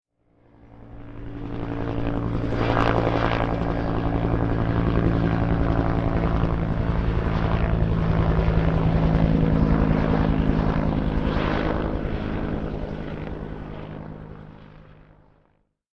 上空ヘリコプター1.mp3